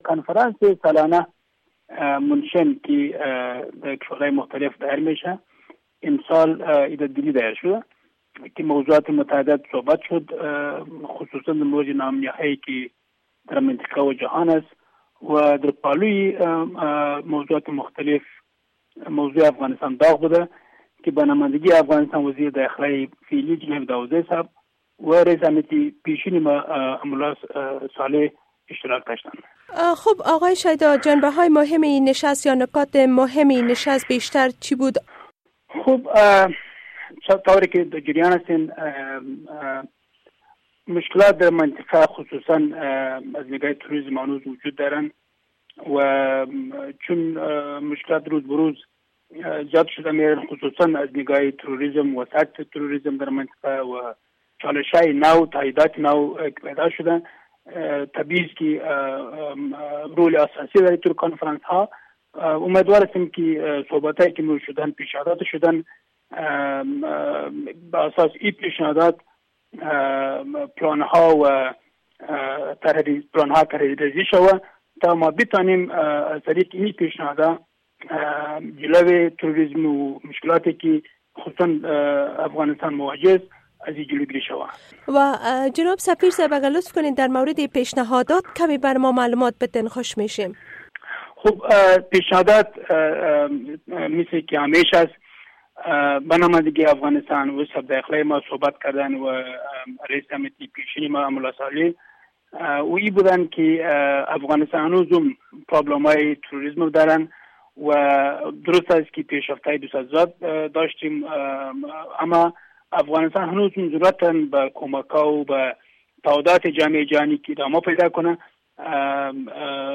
مصاحبه ها
شیدا محمد ابدالی، سفیر افغانستان در هند
آقای ابدالی در گفتگوی ویژه با رادیو آشنا صدای امریکا با اشاره به پاکستان گفت که کشور های منطقه نباید نسبت به این نشست و خواست های افغانستان واکنش منفی داشته باشند.